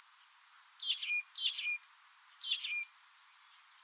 Listen to the singing of three Darwin´s finch species:
Small Ground Finch and a
Song_Small_Ground_Finch.mp3